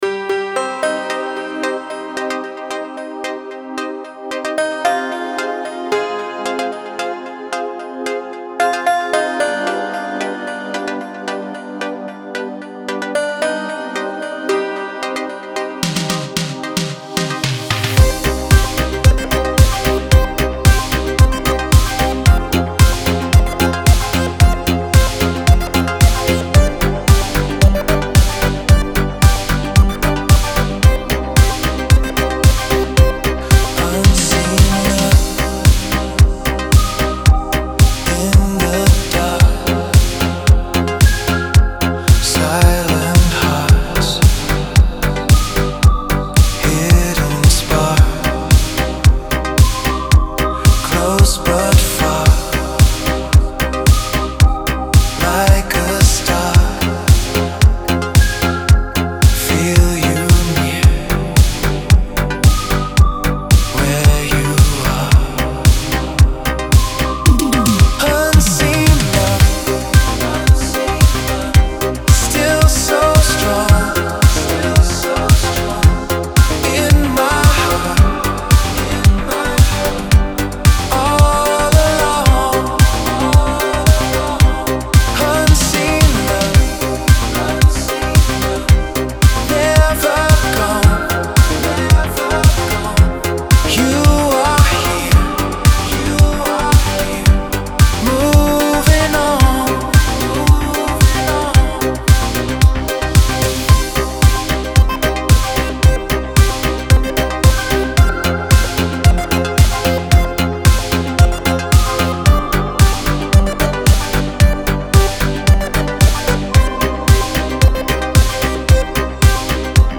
эстрада , dance
диско
танцевальная музыка , pop